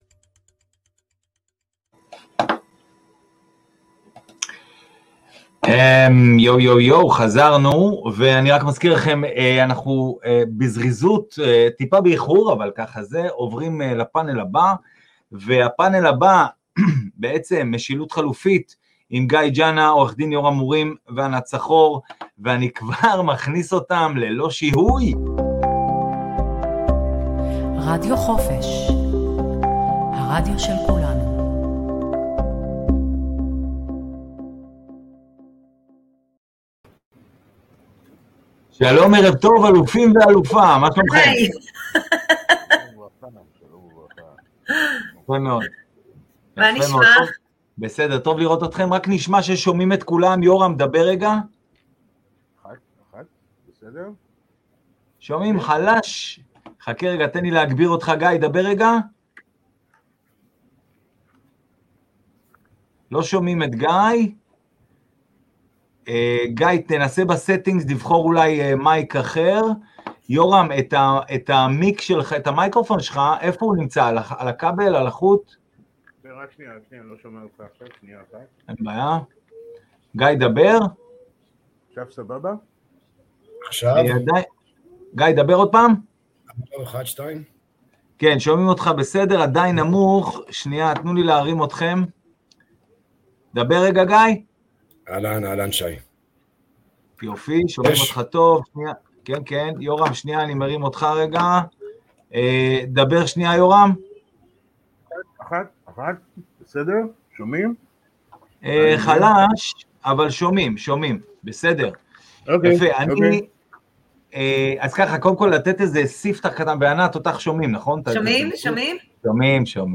פאנל משילות חלופית